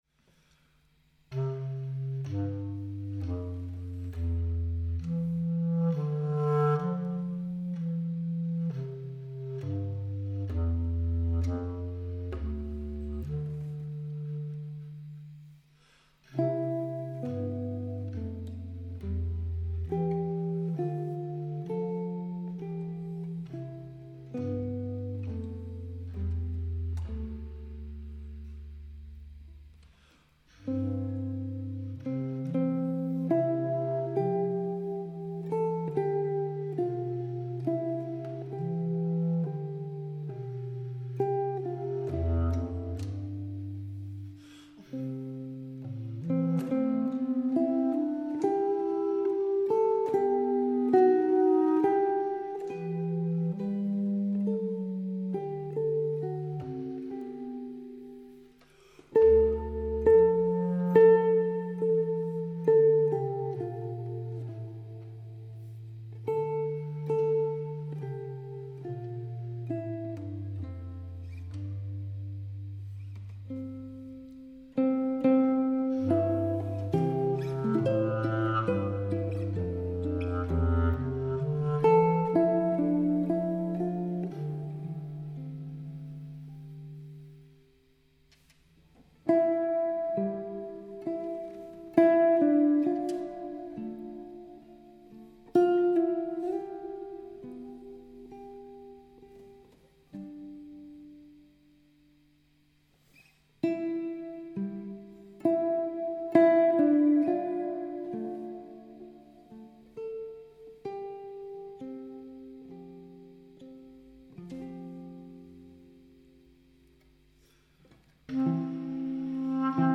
Guitaristen
folkemusikeren
Med nænsomme arrangementer, og en enkelhed i udtrykket, skaber duoen et rum til fordybelse og eftertanke. Her får velkendte sange som Den Blå Anemone, Tit er jeg glad og Hil dig frelser og Forsoner nyt liv – ikke som fællessang, men som instrumentale fortolkninger, hvor tonerne taler på deres egne præmisser.
I kirkens akustik og ro falder musikken naturligt til rette. Musikken bevæger sig i det enkle og inderlige og minder os om, hvor stærkt melodien i sig selv kan bære bådefortælling og følelse.